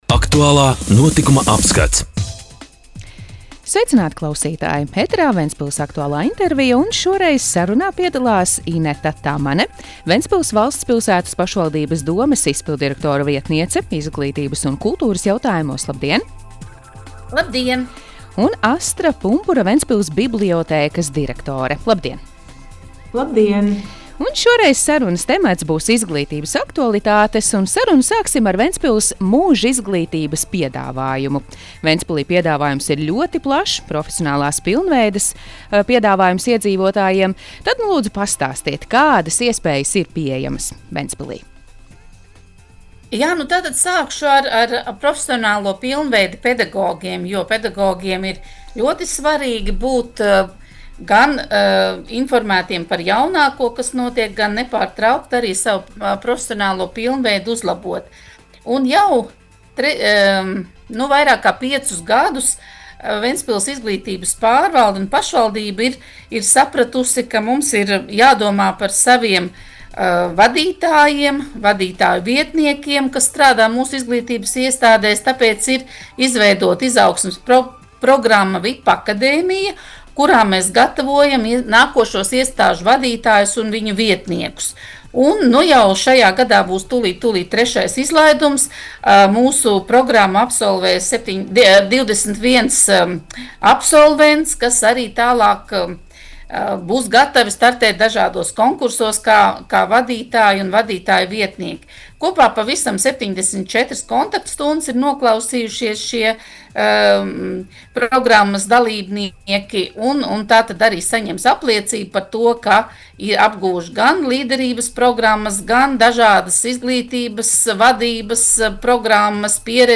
Radio saruna Ventspils Izglītības jomas aktualitātes - Ventspils